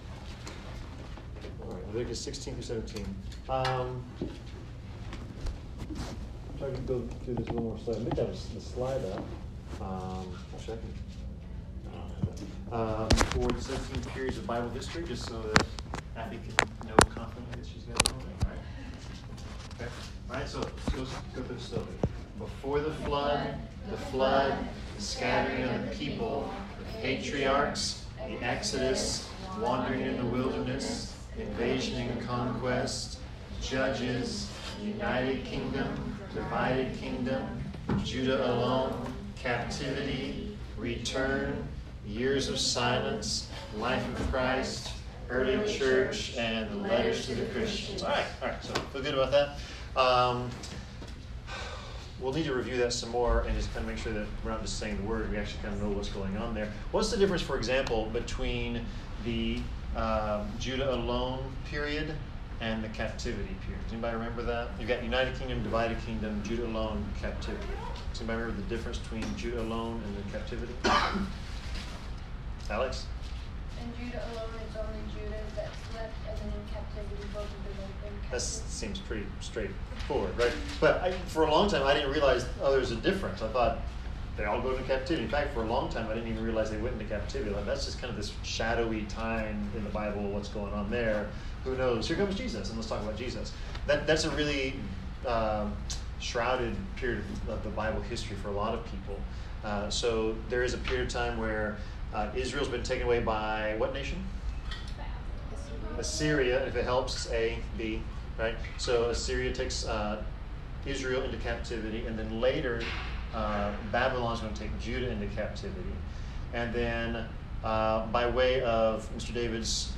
Leviticus 16-17 Service Type: Bible Class The Day of Atonement in Leviticus 16-17 was the most important holiday of the Israelite calendar.